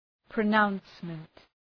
Προφορά
{prə’naʋnsmənt}